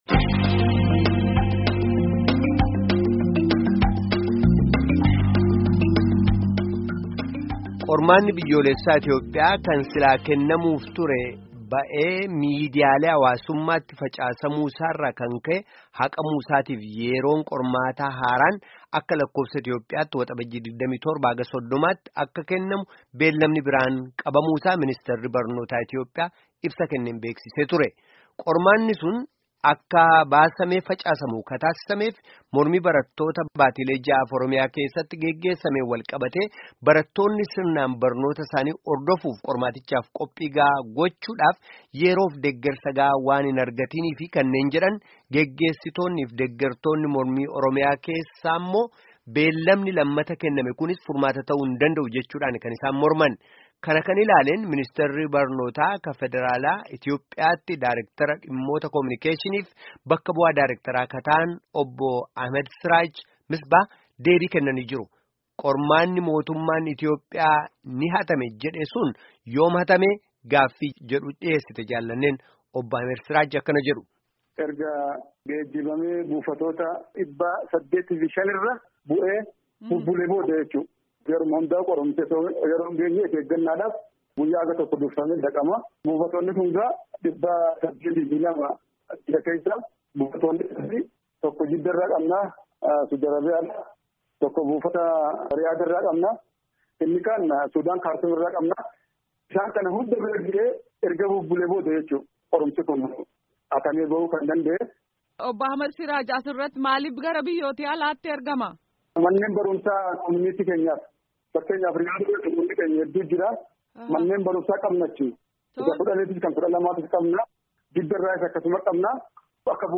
Gabaasa Guutuu Caqasaa